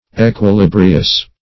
Search Result for " equilibrious" : The Collaborative International Dictionary of English v.0.48: Equilibrious \E`qui*lib"ri*ous\, a. Evenly poised; balanced.